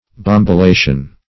Bombilation \Bom`bi*la"tion\, n.